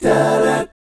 1-DMI7.wav